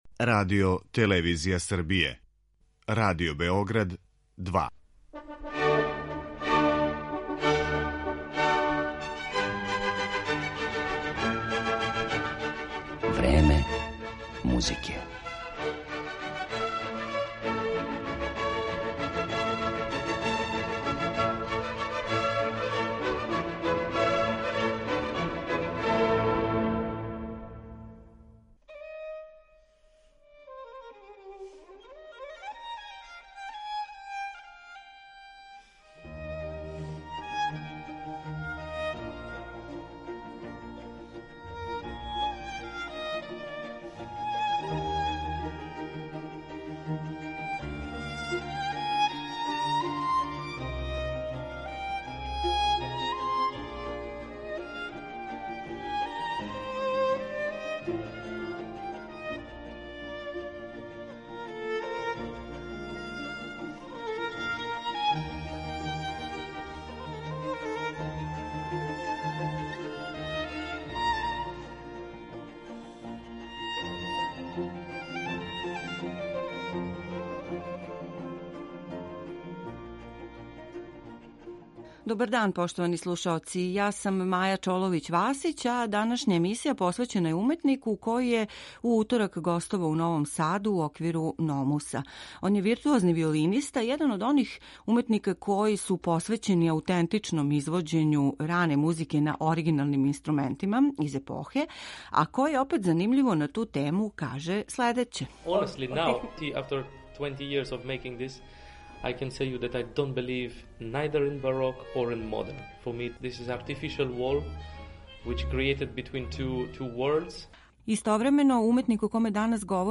U Novom Sadu ovih dana održava se festival NOMUS, u okviru koga je 26. oktobra nastupio Dmitrij Sinkovski ̶ virtuozni violinista, fantastični kontratenor i sve traženiji dirigent, čije uzbudljive interpretacije osvajaju publiku širom sveta.